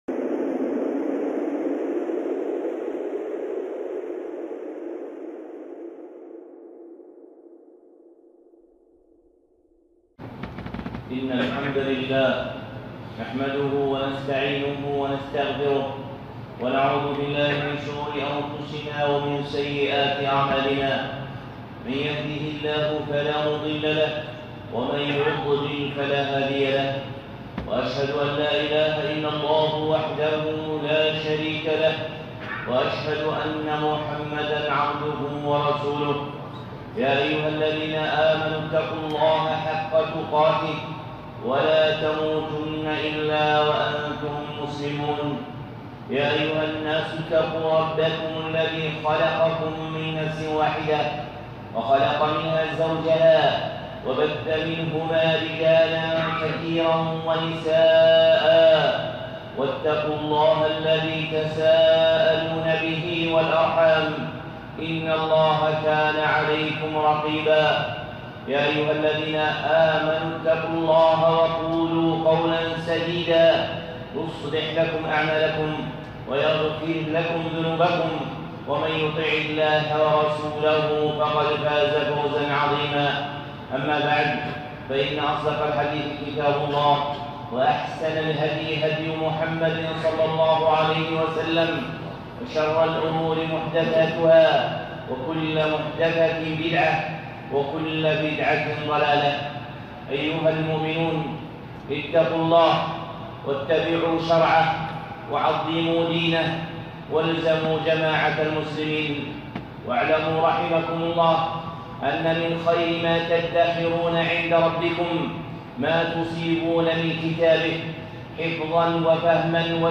خطبة (ذخيرة آية الكرسي)